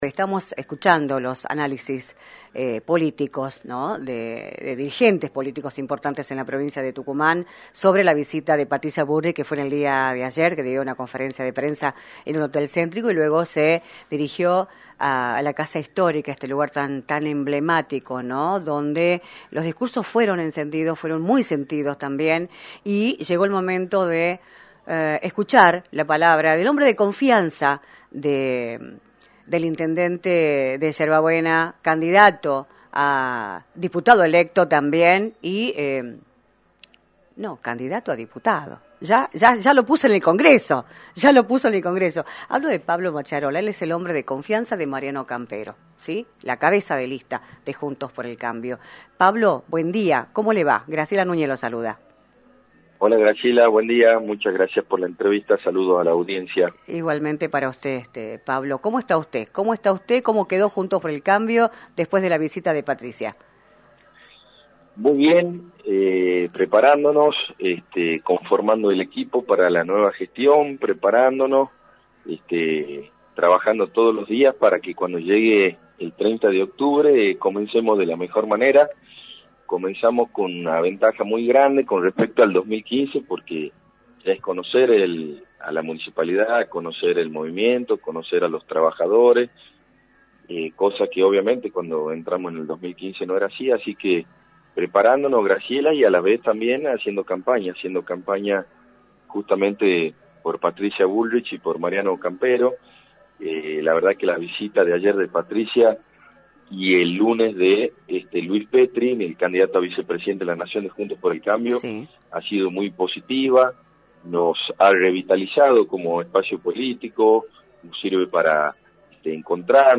Pablo Macchiarola, Intendente electo de Yerba Buena, analizó en «Libertad de Expresión», por la 106.9, las repercusiones de la visita a Tucumán de Patricia Bullrich, en la previa de las elecciones generales del próximo 22 de octubre.